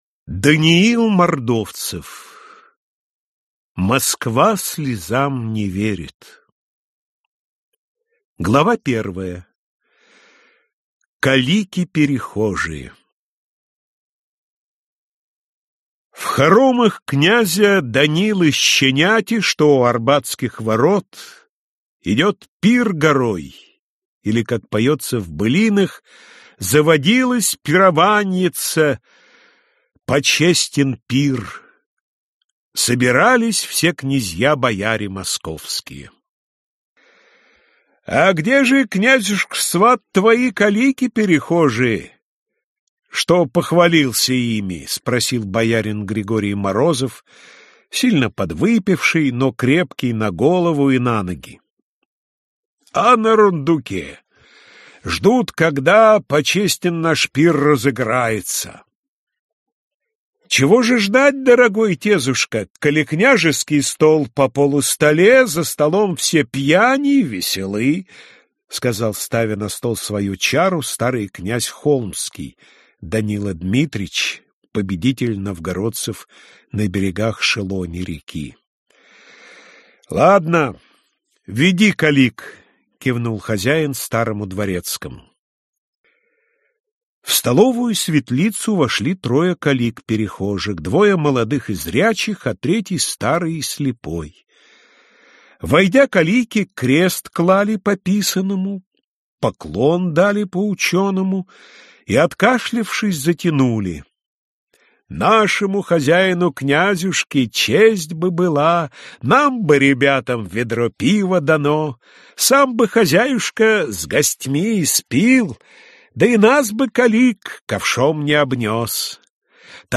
Аудиокнига Москва слезам не верит | Библиотека аудиокниг